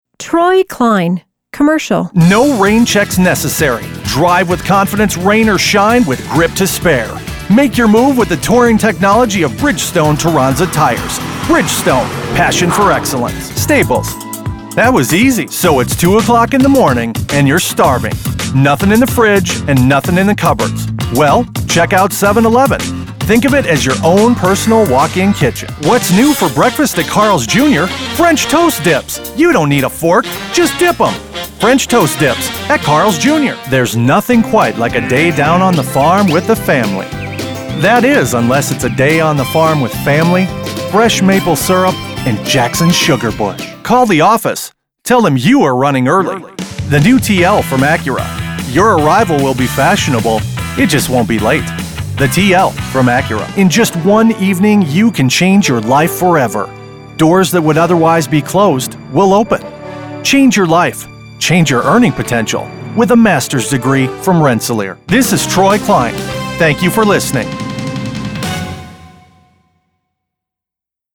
Voiceover Artist Voice Over Artist Voice Talent Commercial Voiceover Documentary Voiceover Audiobook Voiceover Message On Hold Voiceover
englisch (us)
Kein Dialekt
Sprechprobe: Werbung (Muttersprache):